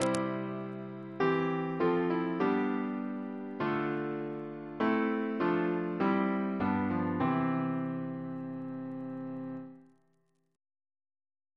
Single chant in C Composer: Henry Smart (1813-1879) Reference psalters: ACB: 280